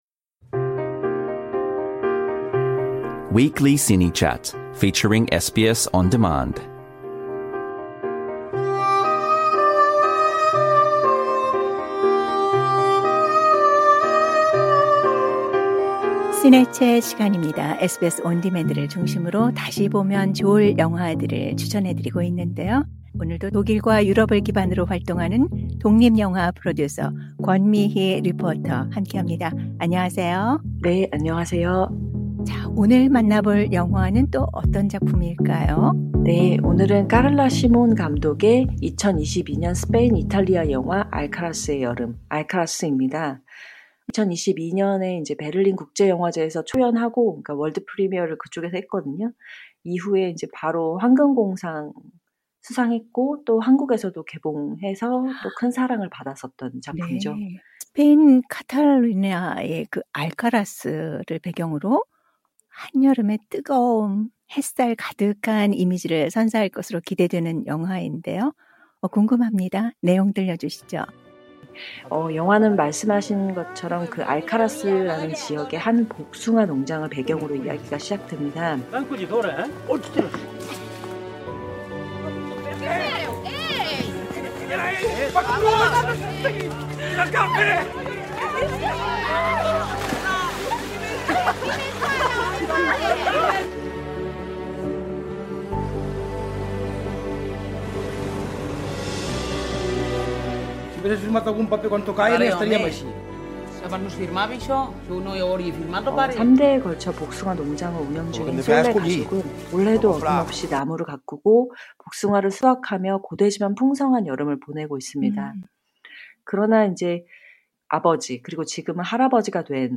[예고편 오디오 클립] 3대에 걸쳐 복숭아 농장을 운영 중인 솔레 가족은 올해도 어김없이 나무를 가꾸고, 복숭아를 수확하며 고되지만 풍성한 여름을 보내고 있습니다.